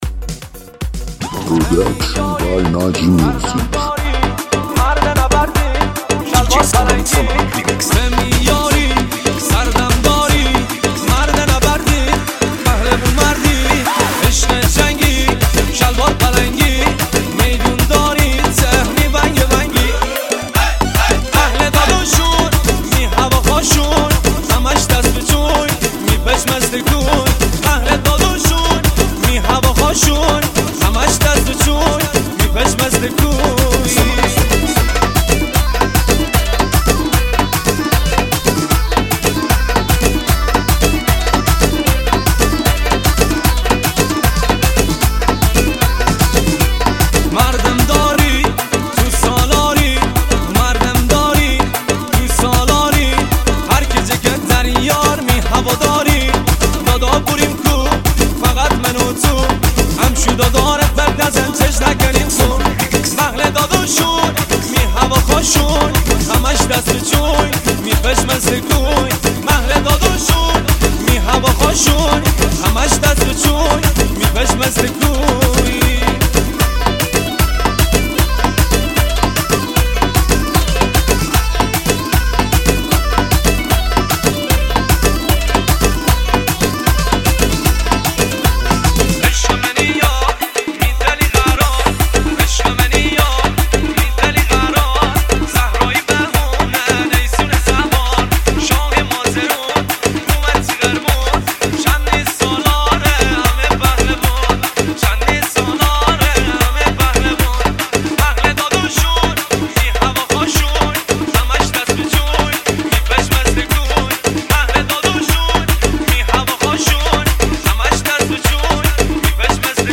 دانلود ریمیکس شاد مازندرانی مخصوص رقص شفتی